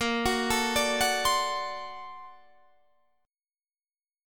Bb+M9 Chord
Listen to Bb+M9 strummed